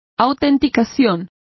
Complete with pronunciation of the translation of authentication.